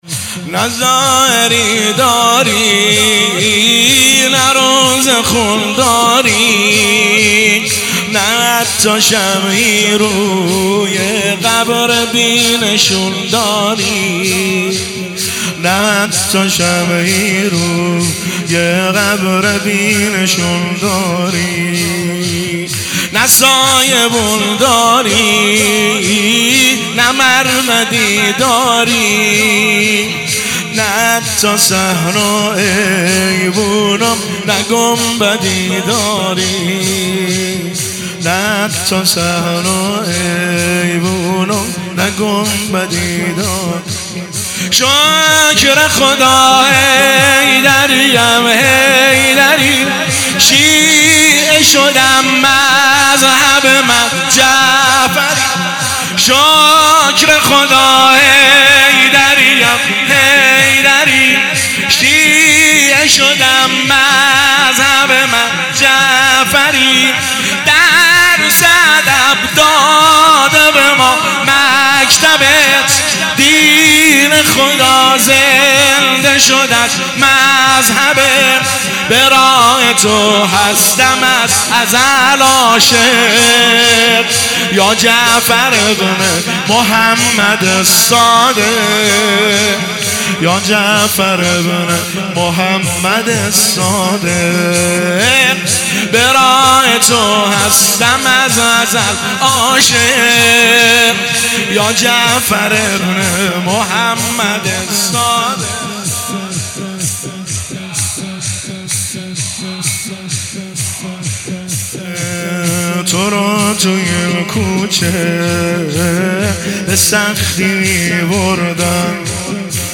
مداحی شور امام صادق